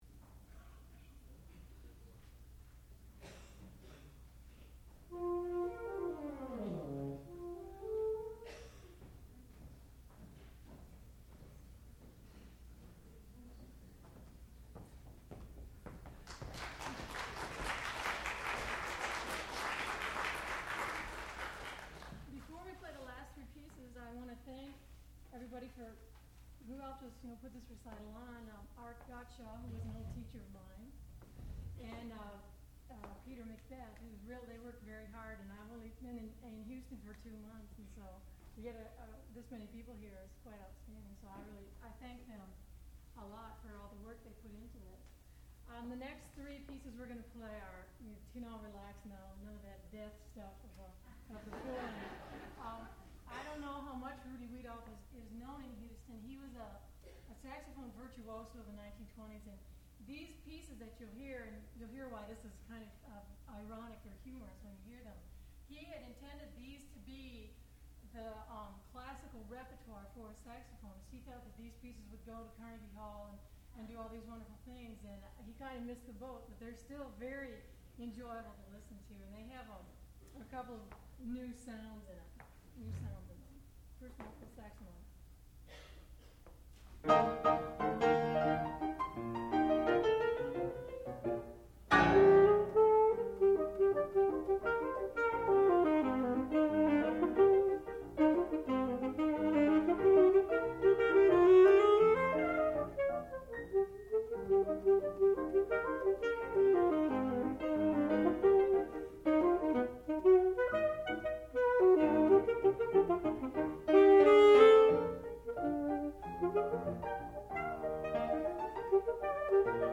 sound recording-musical
classical music
saxophone
piano